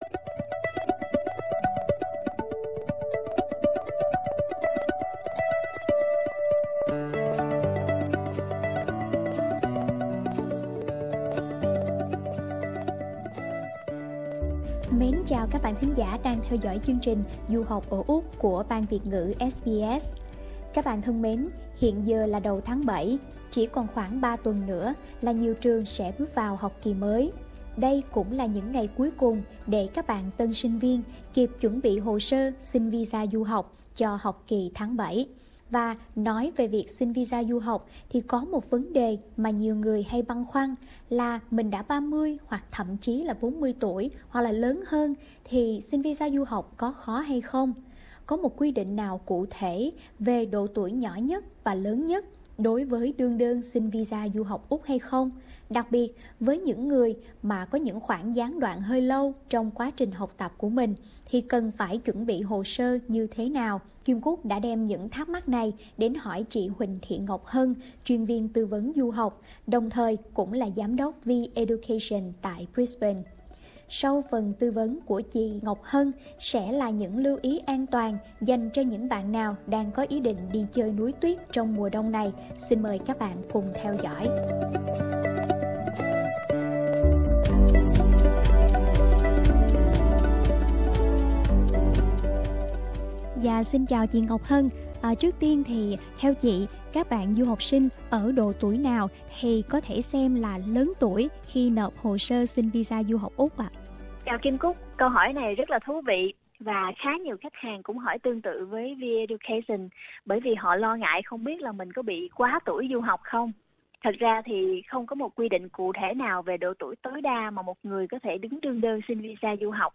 hỏi chuyện